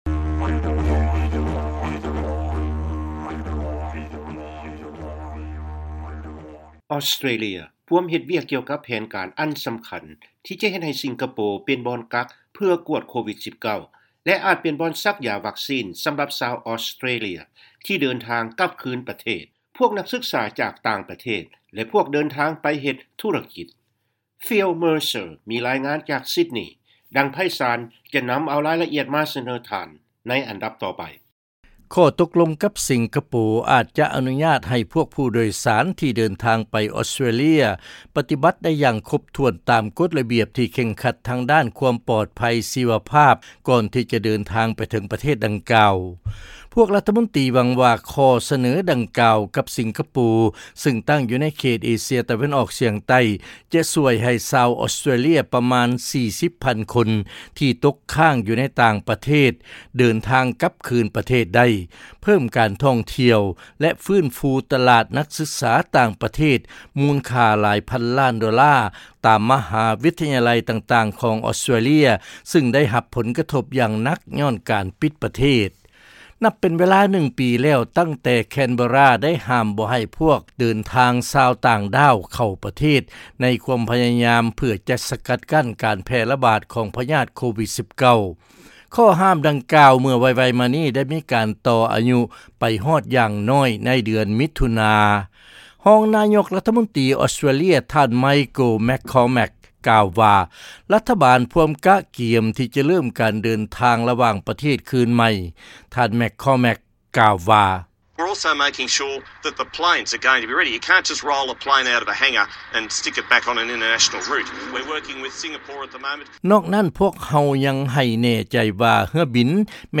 ເຊີນຟັງລາຍງານ ອອສເຕຣເລຍ ວາງແຜນຈະເລີ້ມ ການເດີນທາງ ລະຫວ່າງປະເທດຄືນ ໂດຍຈະໃຫ້ສິງກະໂປ ເປັນບ່ອນກັກໂຕ ສຳລັບກວດໂຄວິດ-19